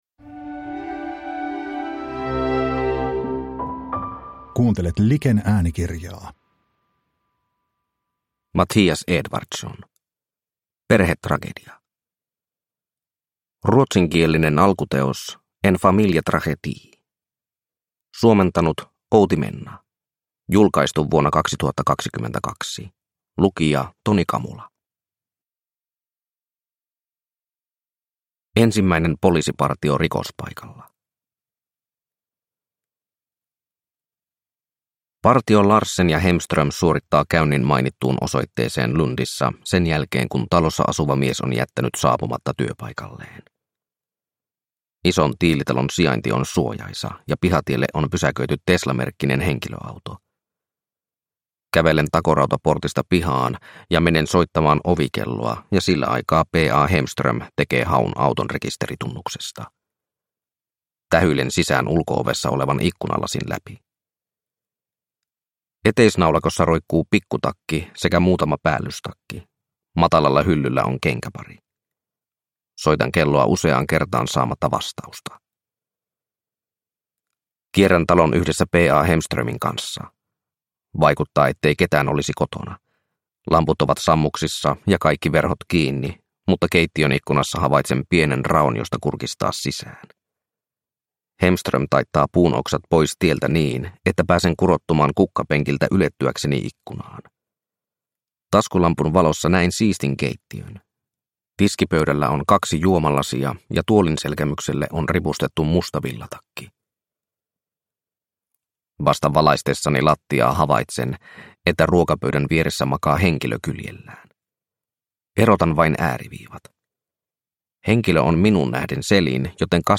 Perhetragedia – Ljudbok – Laddas ner